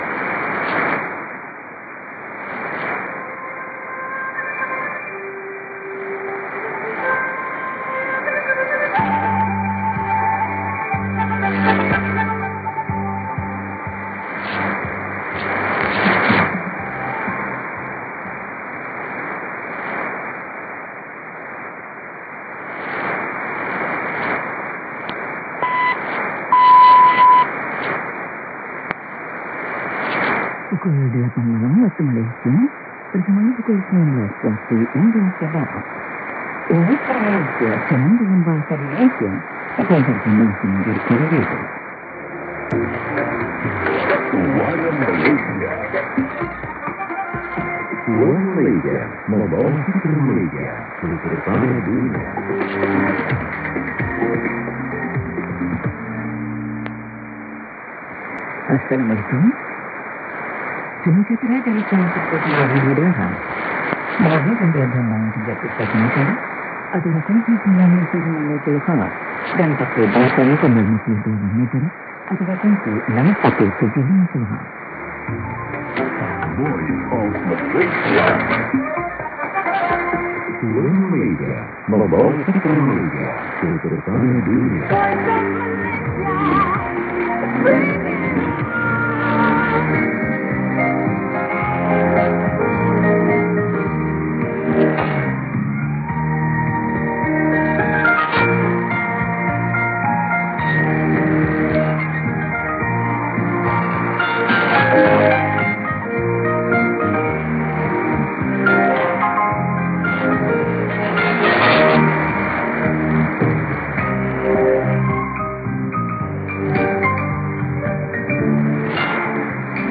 ID: identification announcement
ST: signature tune/jingle